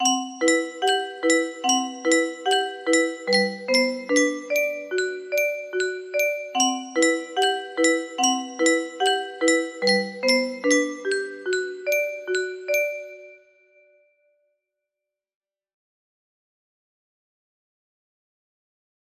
Piano End 4 Song music box melody